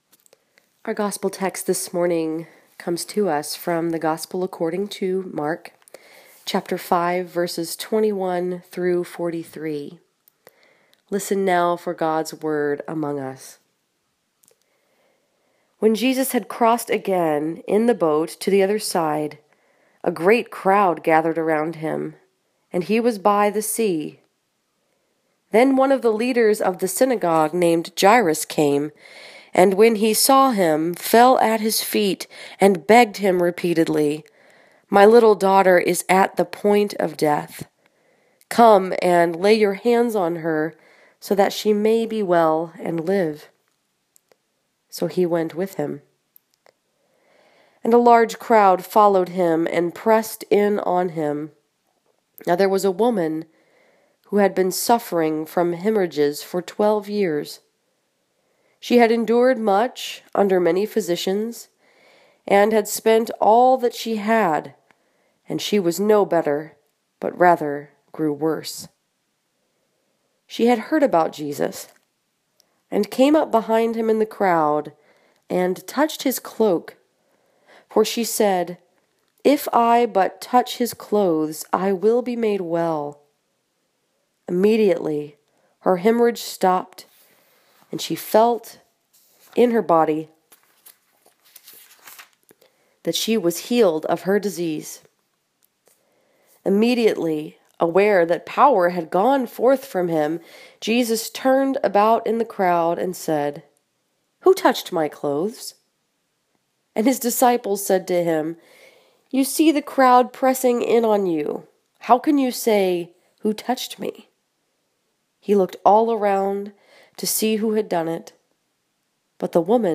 This sermon was preached at St. Andrew’s Presbyterian Church in Dearborn Heights, Michigan and was focused upon Mark 5:21-43.